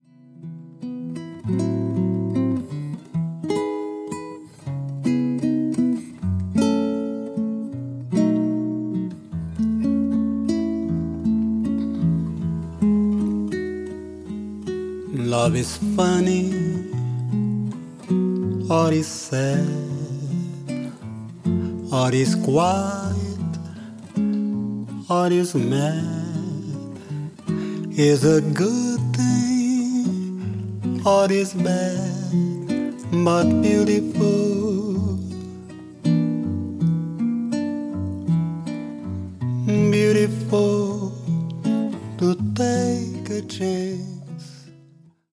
ギターと声だけ。
まろやかなその歌は円熟の極み。ギター弾き語りでジャズやブラジル音楽のスタンダードを歌う。
vo,g